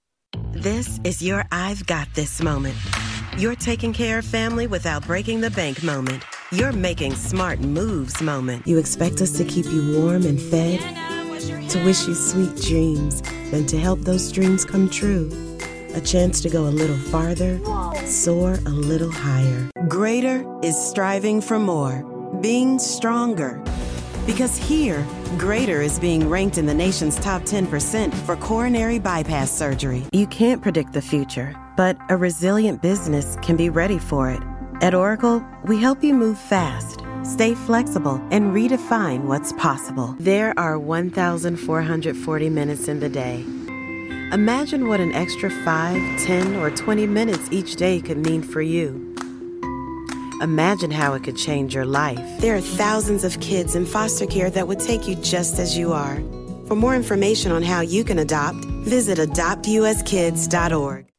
Inglés (Americano)
Llamativo, Seguro, Natural, Suave, Empresarial
Comercial
She works from an amazing home studio with professional equipment.